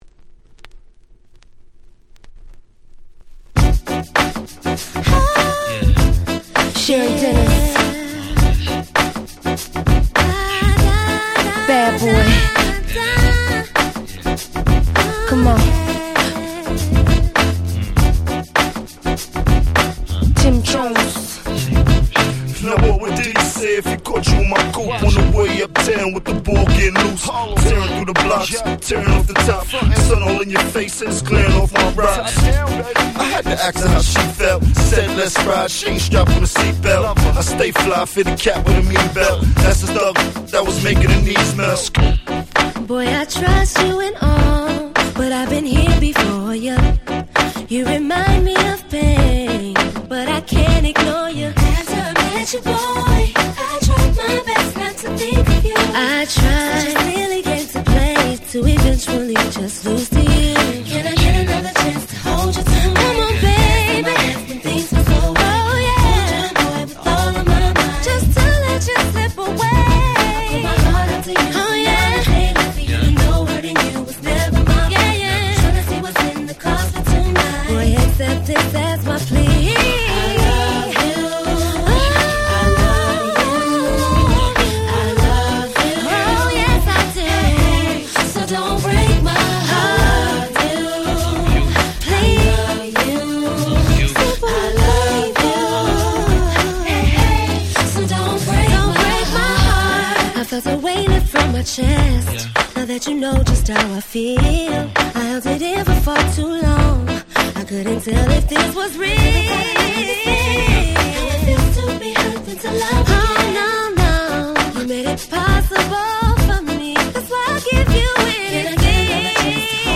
06' Smash Hit R&B !!